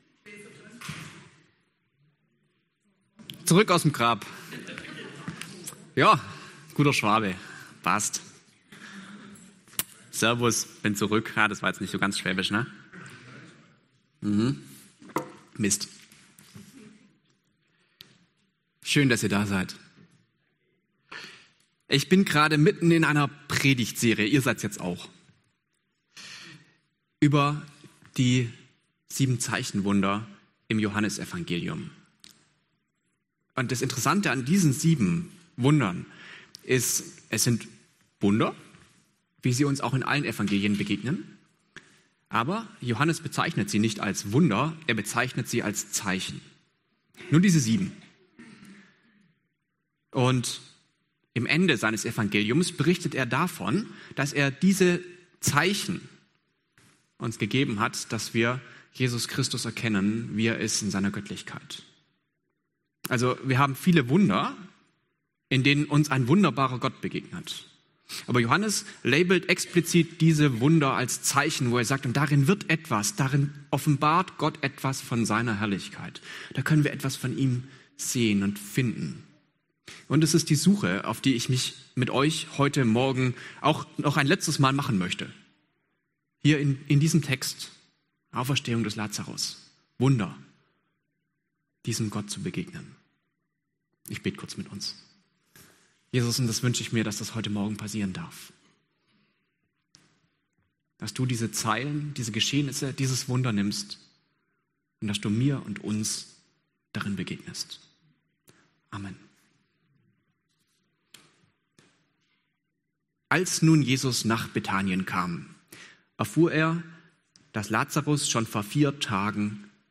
Zeichen nach Johannes Passage: Johannes 11 Dienstart: Gottesdienst Themen